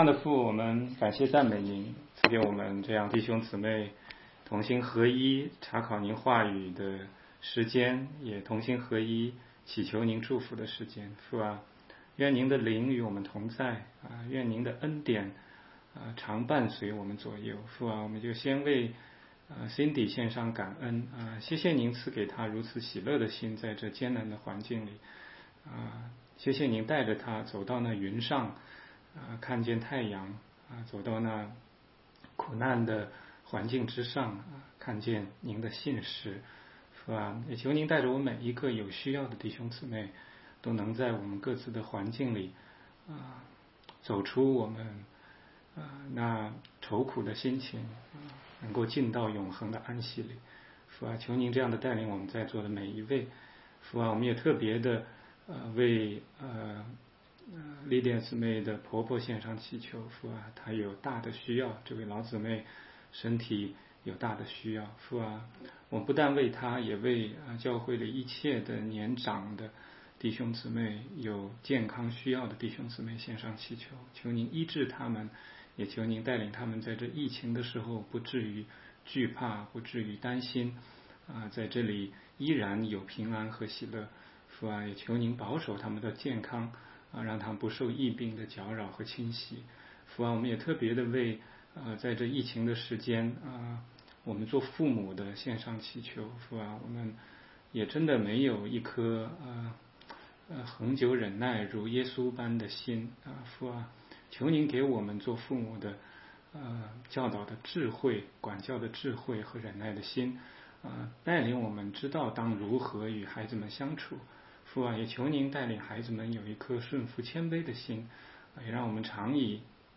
16街讲道录音 - 雅各书1章1-2节：你的信心经过试炼了吗？